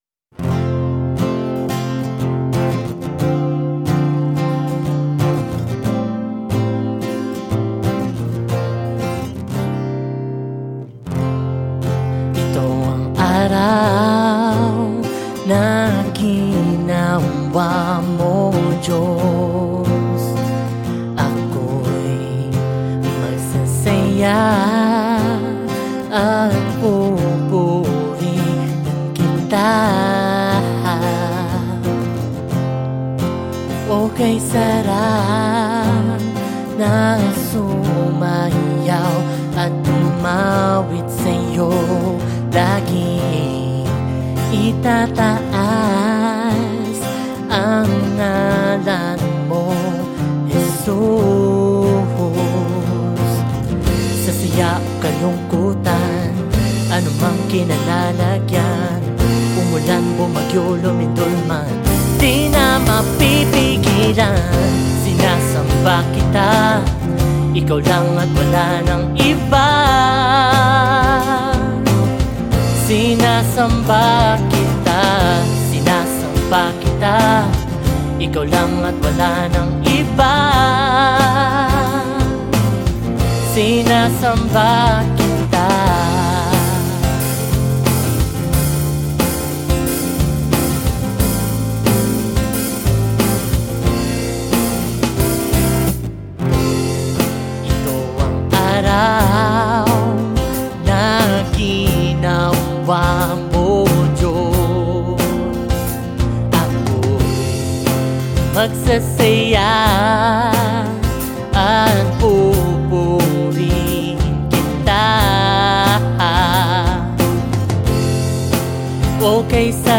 62 просмотра 44 прослушивания 0 скачиваний BPM: 180